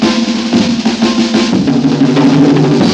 JAZZ BREAK 5.wav